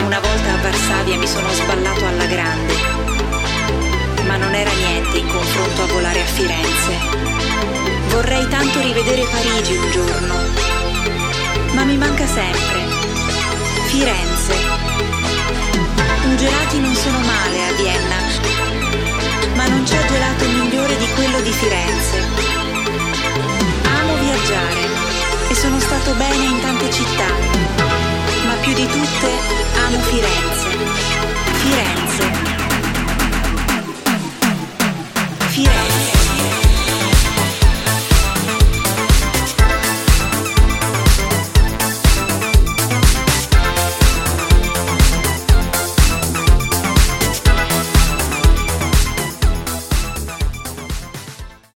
コミカルな80sサウンドをモダンなセンスで昇華した、ナイスな1枚です！